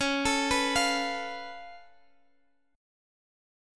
Db+7 chord